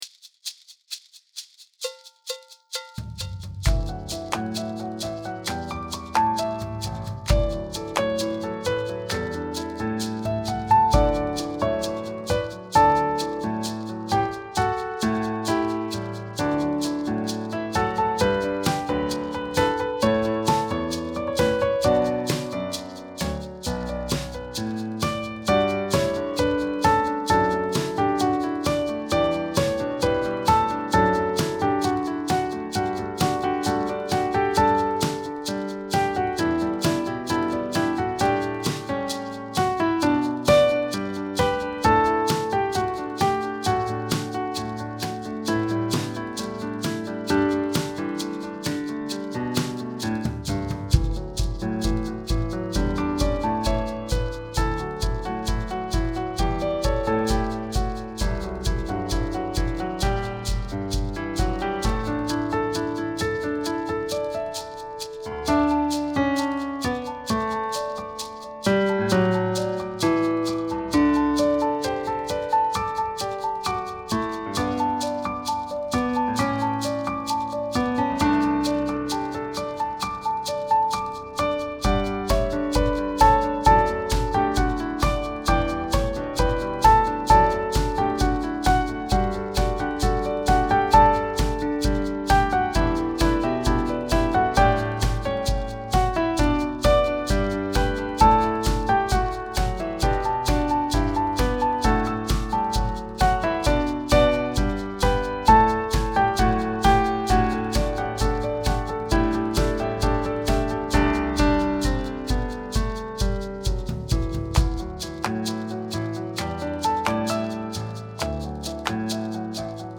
an energetic, syncopated pop-style ensemble arrangement
• Syncopated rhythm and steady beat in 4/4